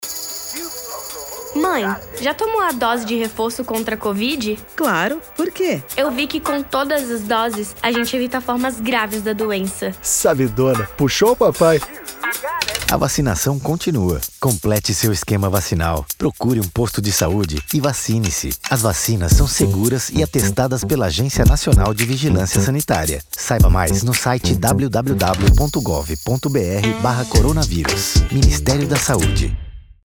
Spot - Reforço Vacinal. mp3